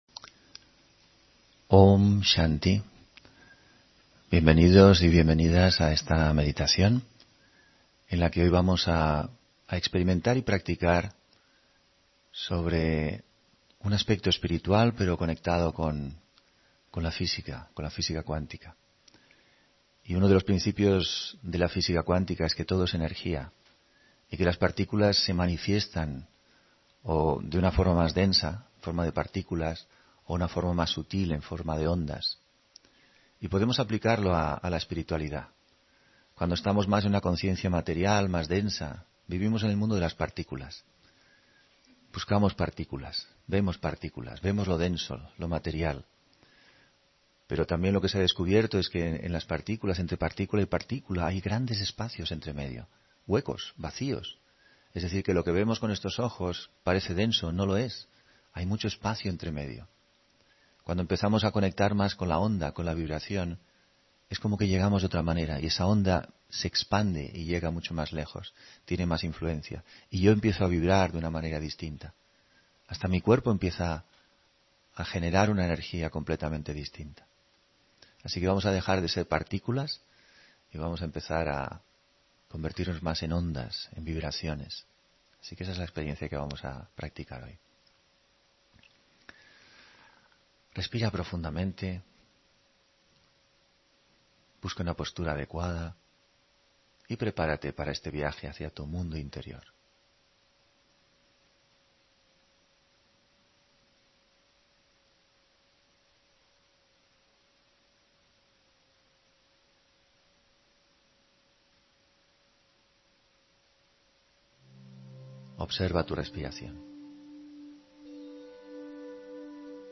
Meditación de la mañana